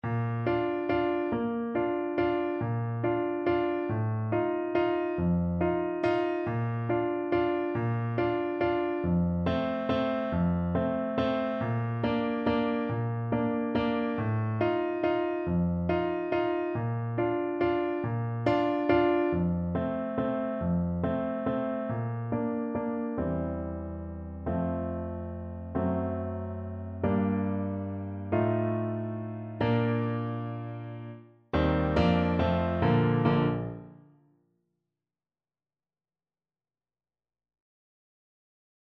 3/4 (View more 3/4 Music)
Moderato =140
Tenor Saxophone  (View more Easy Tenor Saxophone Music)
Classical (View more Classical Tenor Saxophone Music)